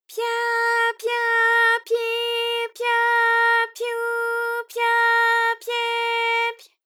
ALYS-DB-001-JPN - First Japanese UTAU vocal library of ALYS.
pya_pya_pyi_pya_pyu_pya_pye_py.wav